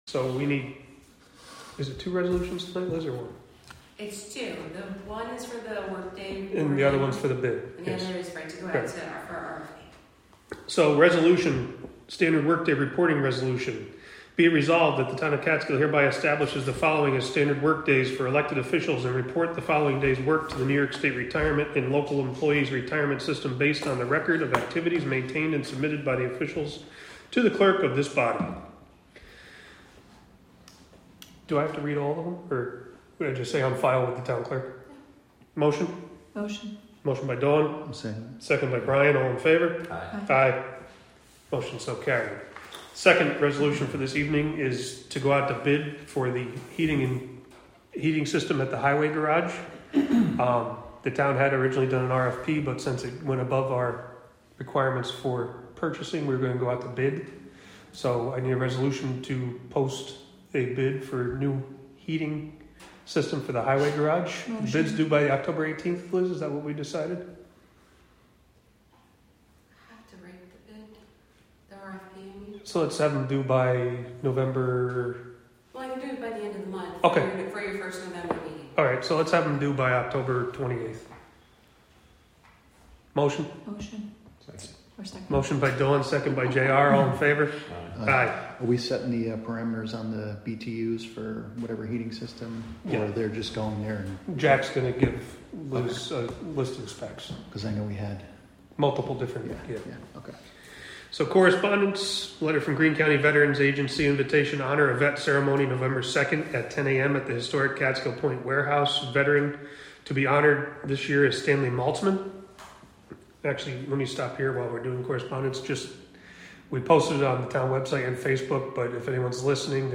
Live from the Town of Catskill: October 1, 2024 Catskill Town Board Meeting (Audio)